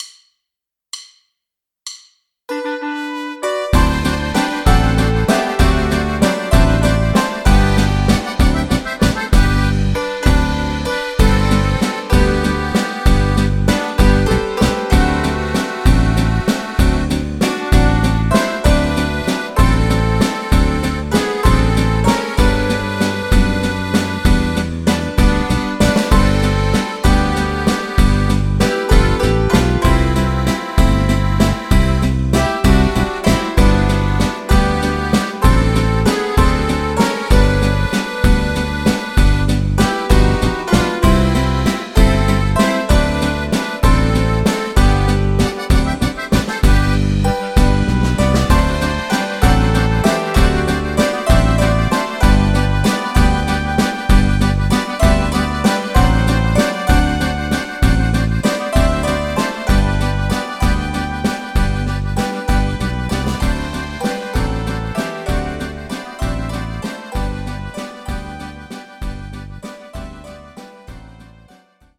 karaoke, strumentale